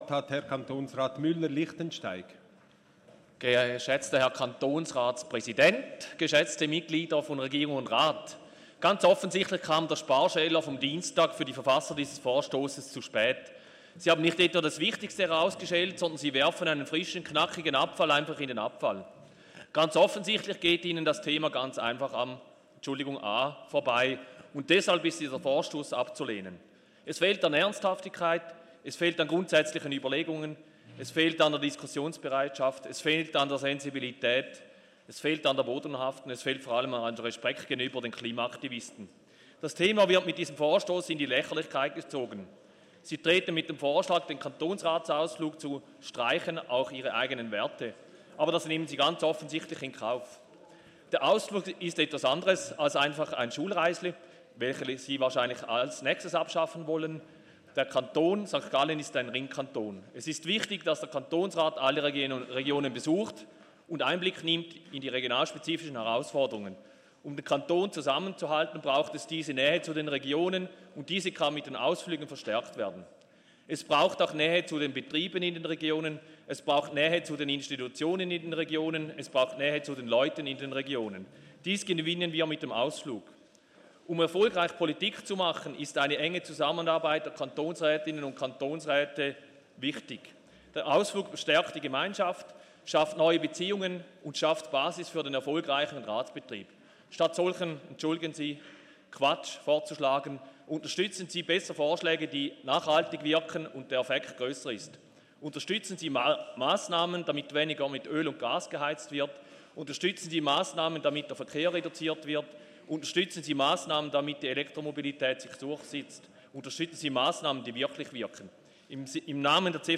13.6.2019Wortmeldung
Session des Kantonsrates vom 11. bis 13. Juni 2019